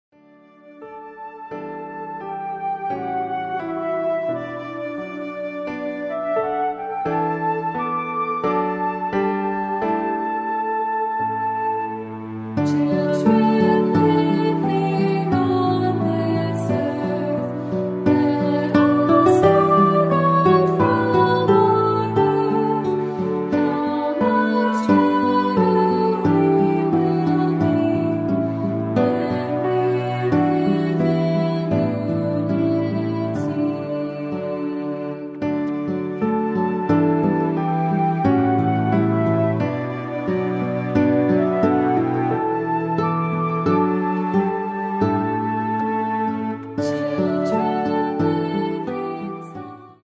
unison choral arrangement
piano and flute